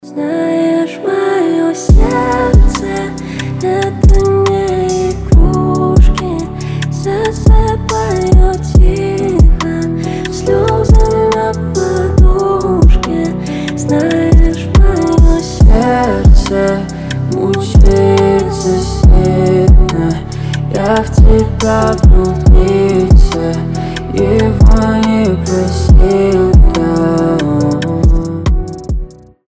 лирика
спокойные
дуэт
медленные
акустика
нежные
красивый вокал